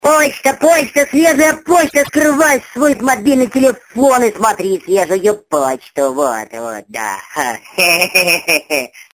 » Почта, свежая почта (типа масяня) Размер: 110 кб